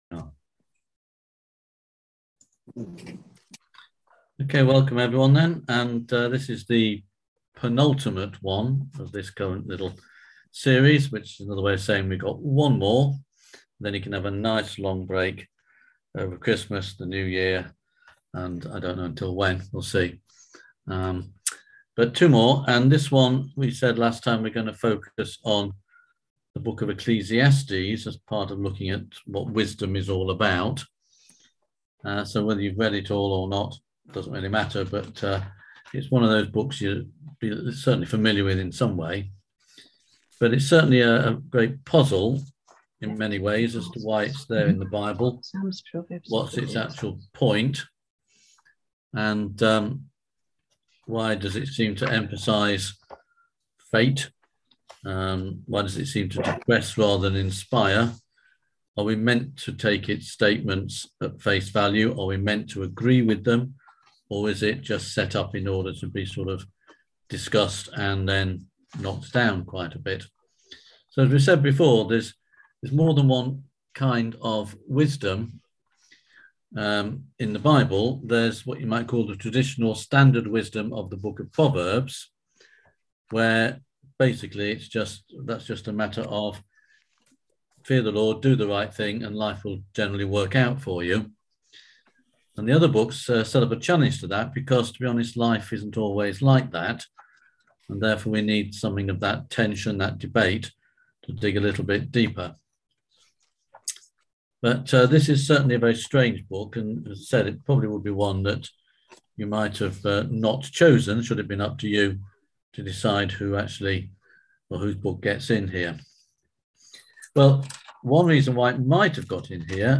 On November 24th at 7pm – 8:30pm on ZOOM